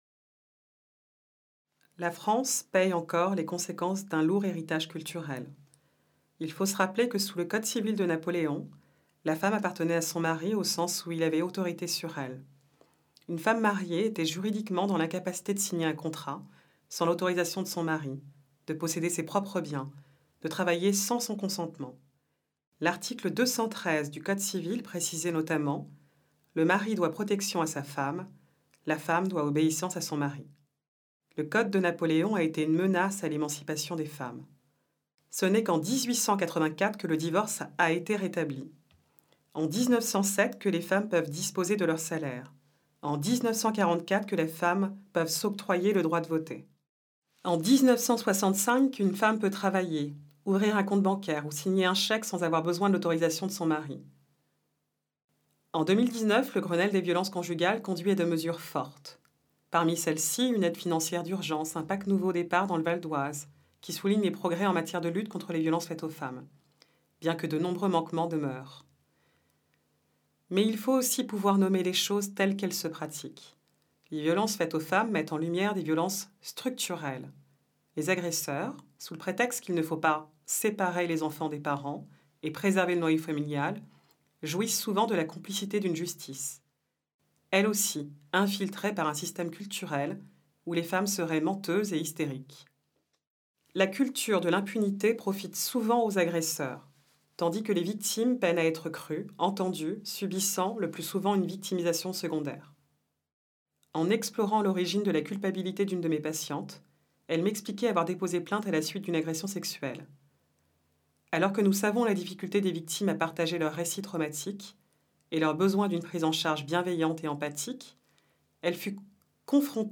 Profitez des articles du Mag’ VIOLENCES en version audio et accédez également aux commentaires des articles par une psychologue, ainsi qu’à des conseils utiles !